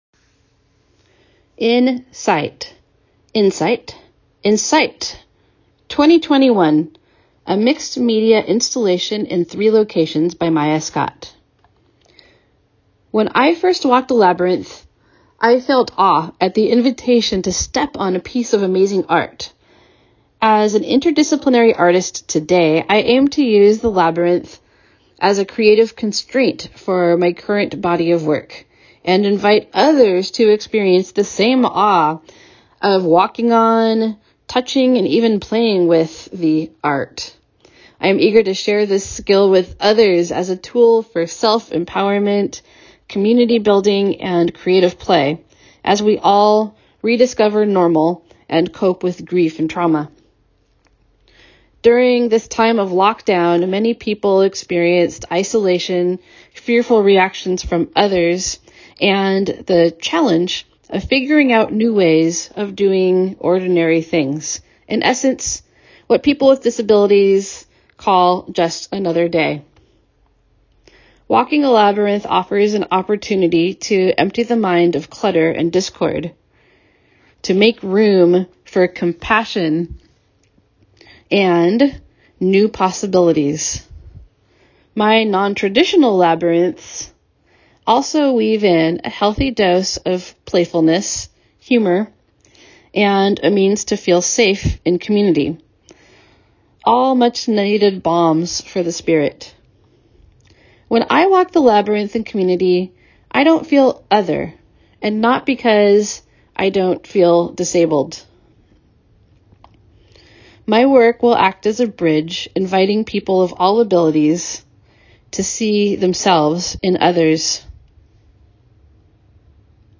Audio description of all the artworks